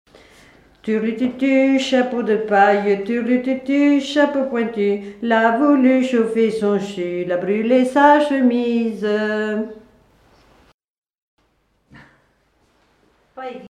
Enfantines - rondes et jeux
Veillées de chanteurs traditionnels
Pièce musicale inédite